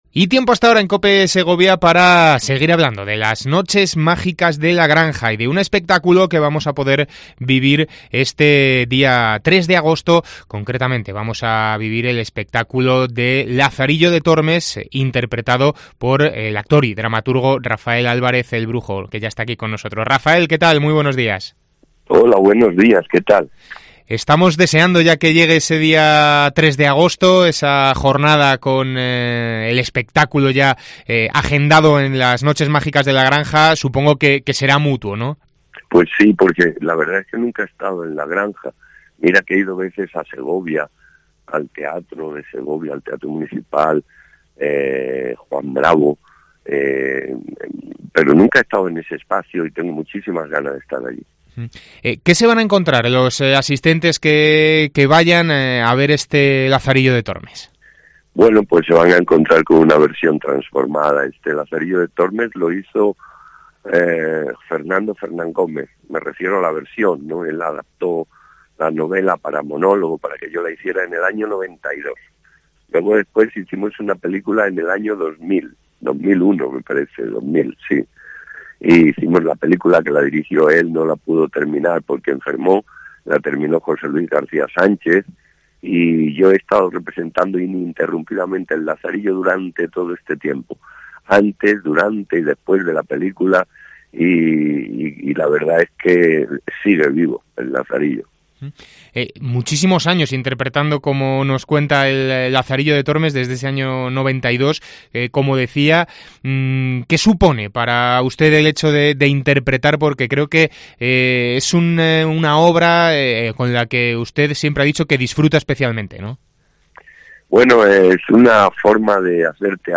Entrevista con Rafael Álvarez, El Brujo.